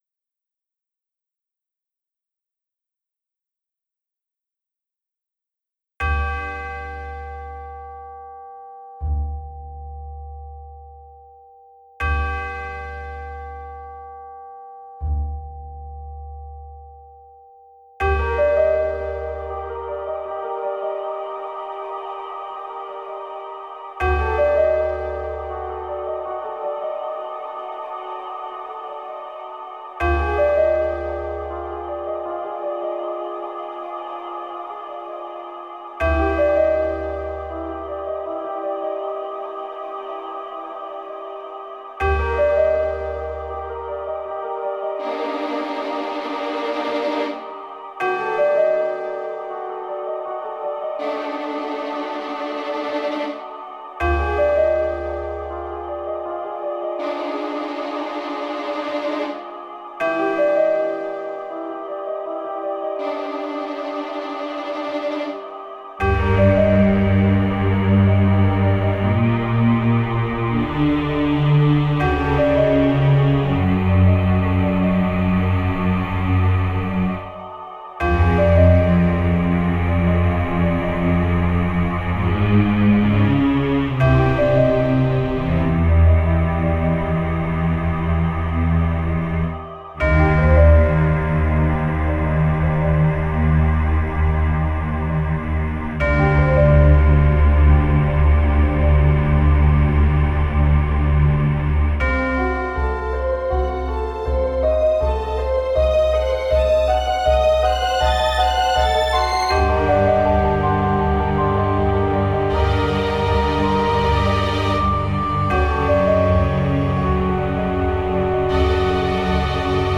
The strongest influence on this track is from the Shrine Theme of Breath of the Wild, and given that BOTW is somewhat of a return to form for the Legend of Zelda series, it seemed appropriate. I also threw in a little bit of the tone of Arrival in at the beginning (accidentally).